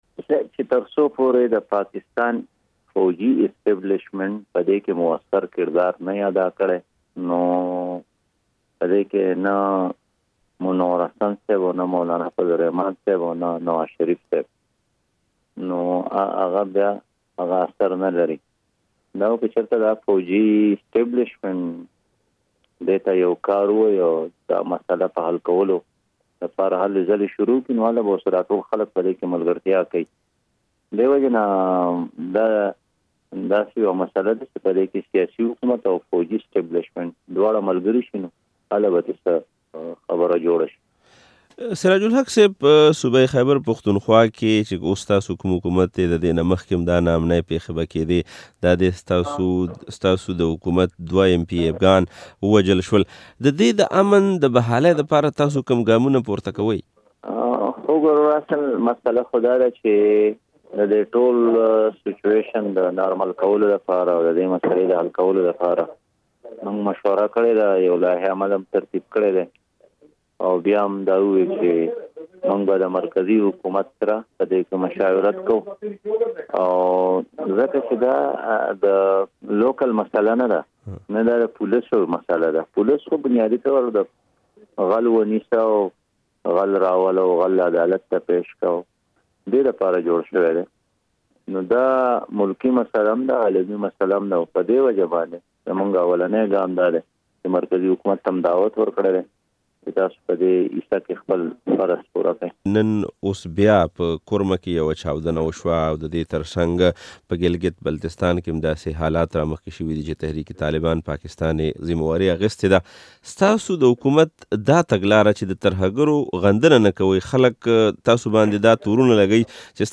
له سراج الحق سره مرکه